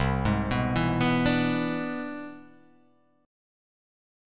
C+M9 chord